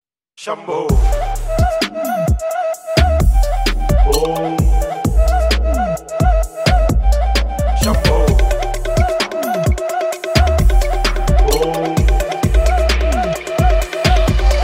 BGM Ringtone